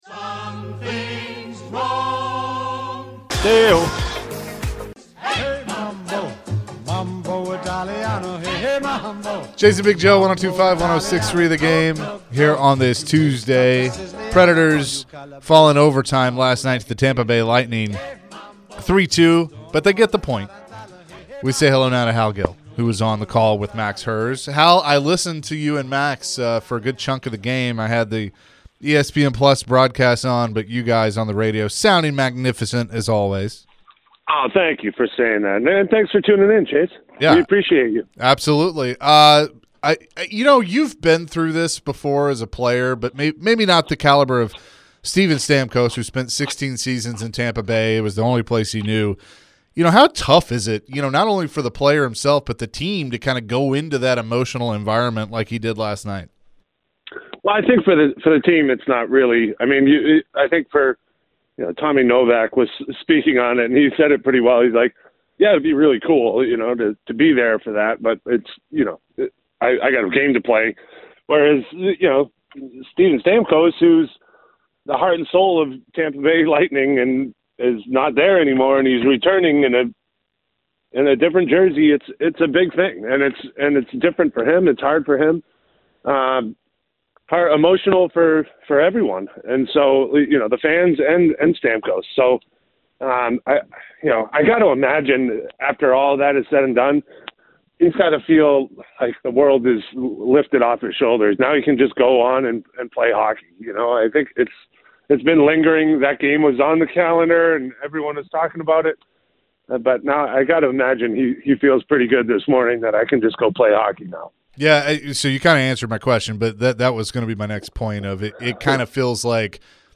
Preds radio & TV analyst Hal Gill joined the show to discuss Steven Stamkos' return to Tamp Bay. Why are the Preds off to a slow start this season? What does Hal think the Preds need to do to turn things around?